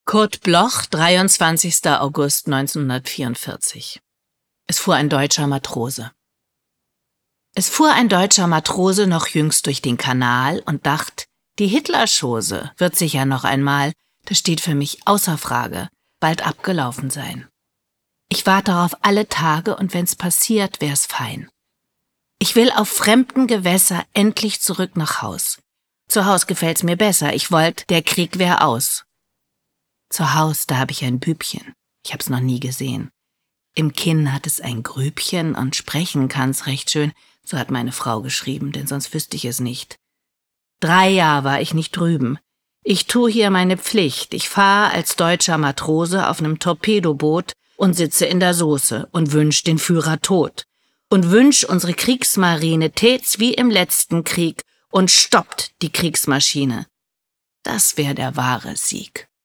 Catrin Striebeck (* 1966) is een Duitse actrice.